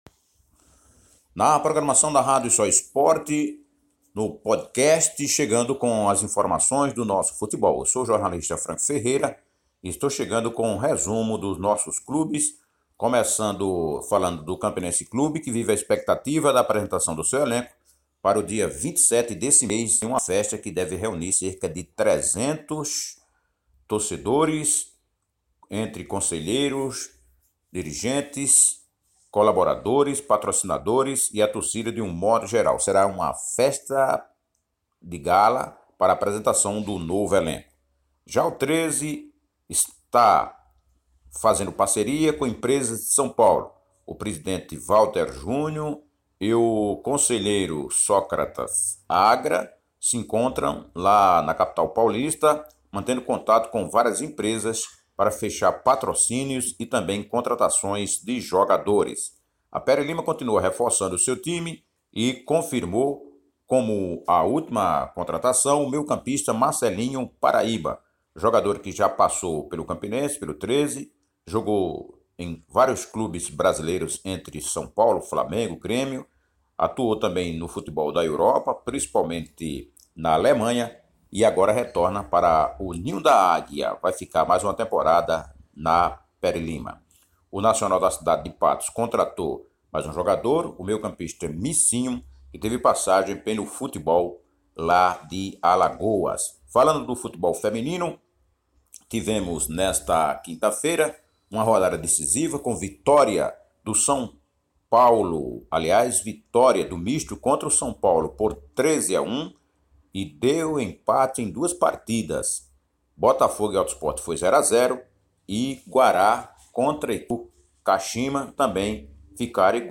Rádio Soesporte Podcast informações esportivas da Paraíba com o jornalista